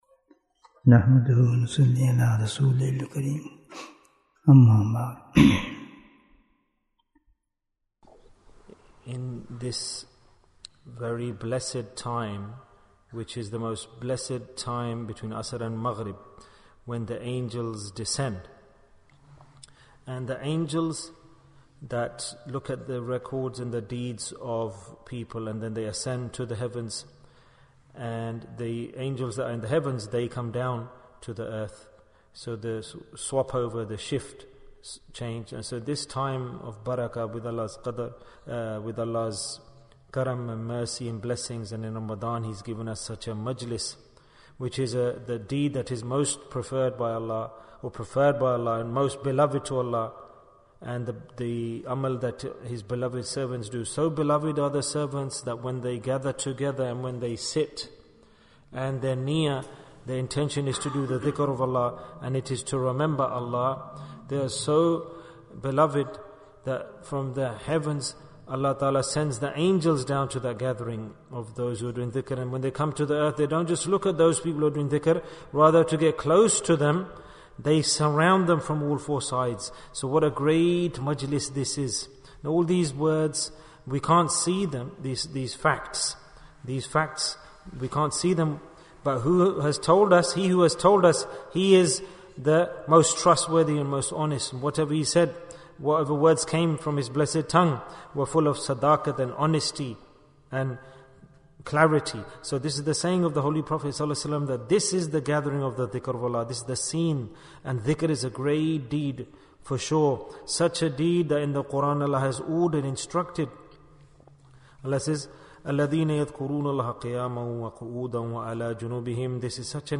Why is Dhikr Important in Ramadhan? Bayan, 23 minutes4th April, 2023